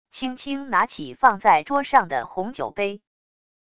轻轻拿起放在桌上的红酒杯wav音频